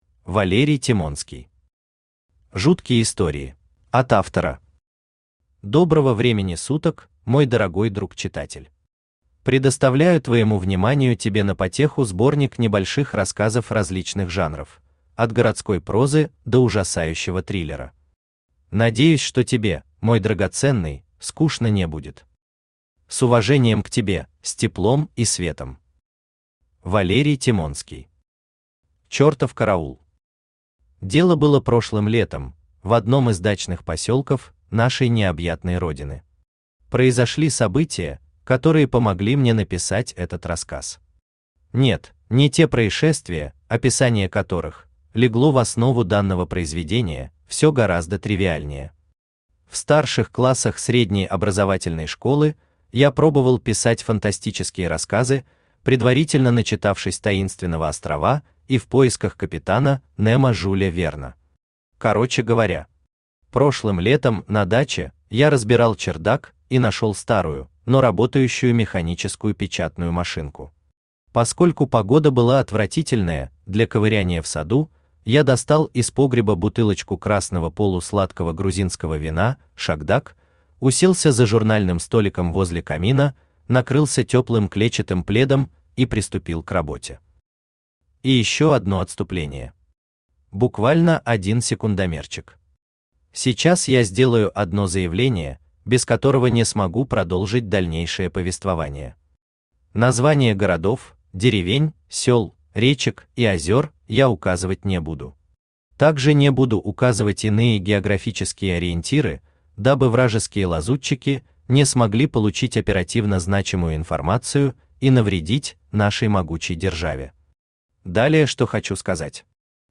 Аудиокнига Жуткие истории | Библиотека аудиокниг
Aудиокнига Жуткие истории Автор Валерий Анатольевич Тимонский Читает аудиокнигу Авточтец ЛитРес.